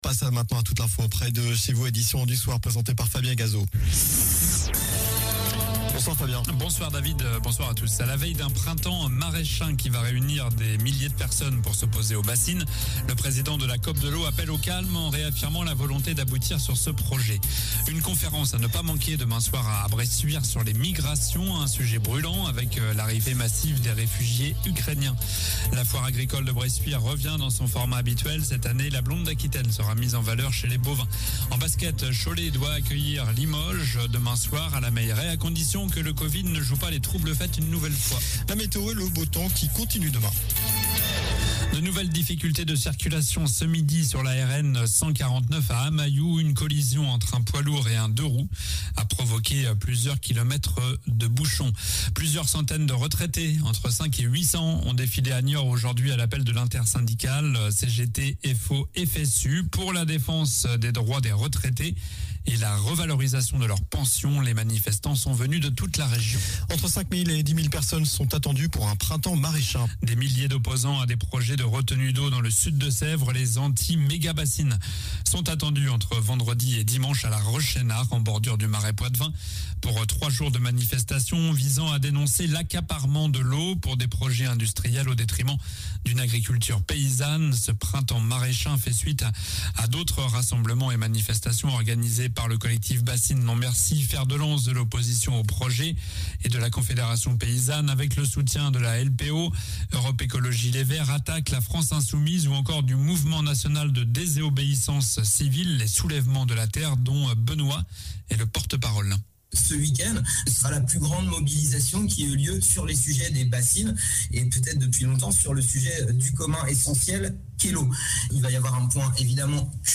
Journal du jeudi 24 mars (soir)